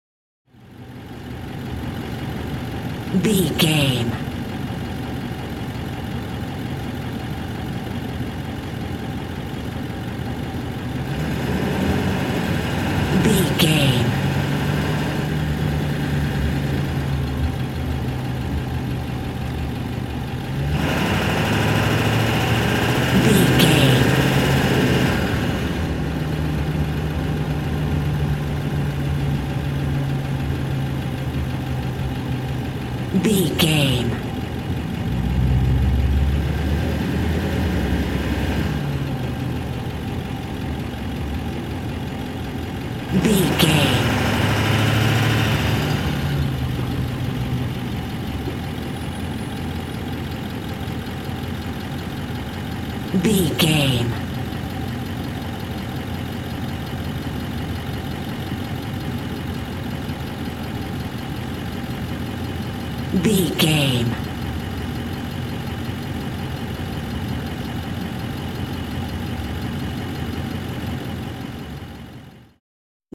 Ambulance Int Drive Diesel Engine Idle Slow
Sound Effects
No